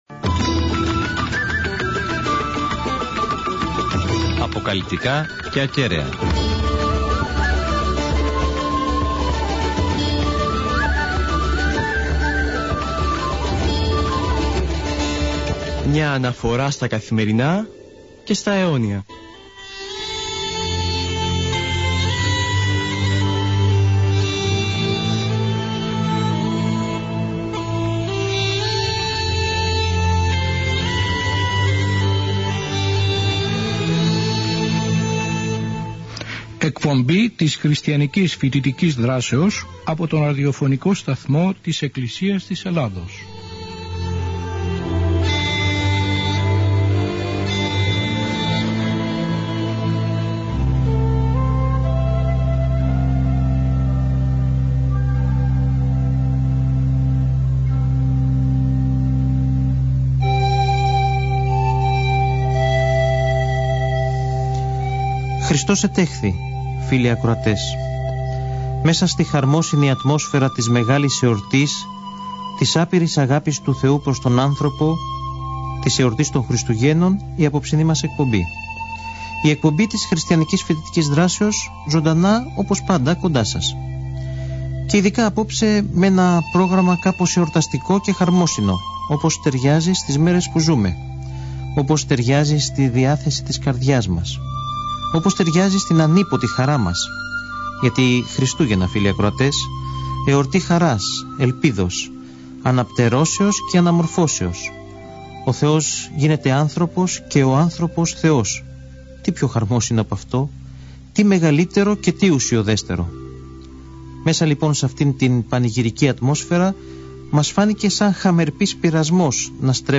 Η πρώτη ανάρτηση ηχογραφημένης εκπομπής της Χ.Φ.Δ. είναι γεγονός!
Στη διάρκεια της εκπομπής, διάφοροι καλεσμένοι με τηλεφωνικές παρεμβάσεις δίνουν τον παλμό του εορτασμού της γέννησης του Χριστού σε κάθε γωνιά της γης μας: